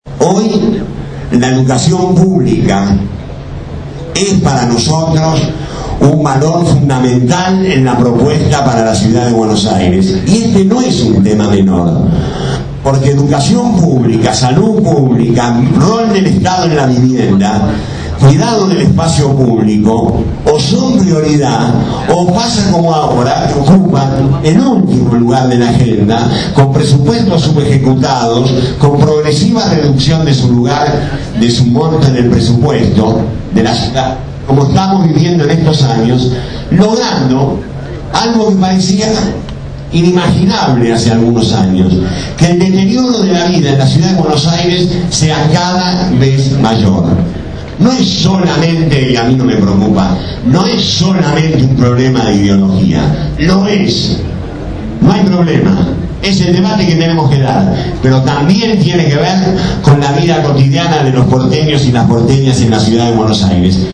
Con la presencia del Ministro de Trabajo Carlos Tomada quedó inaugurada la Escuela Popular de Medios Comunitarios.
En su intervención el Ministro de Trabajo Carlos Tomada valoró el rol de la educación pública.